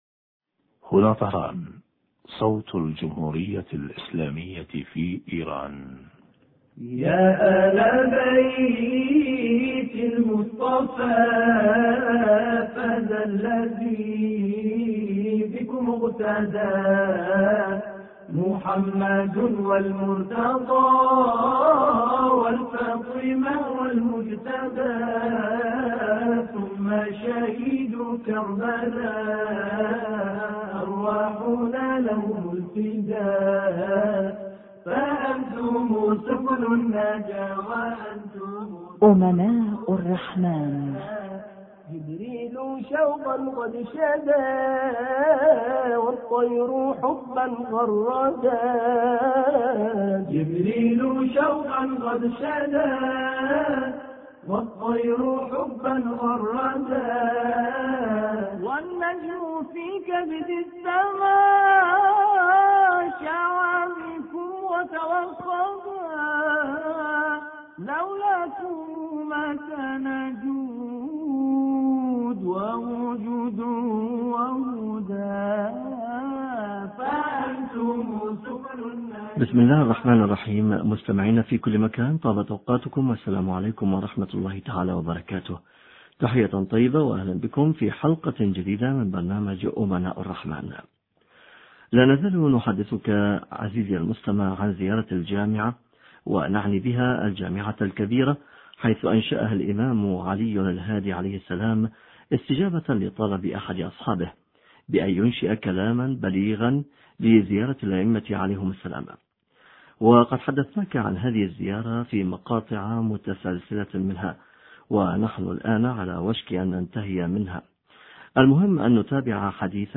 شرح فقرة: وشأنكم الحق والصدق والرفق حوار